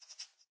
sounds / mob / rabbit / idle2.ogg